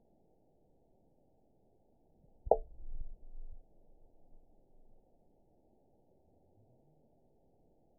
event 920457 date 03/26/24 time 18:23:35 GMT (1 year, 2 months ago) score 9.51 location TSS-AB01 detected by nrw target species NRW annotations +NRW Spectrogram: Frequency (kHz) vs. Time (s) audio not available .wav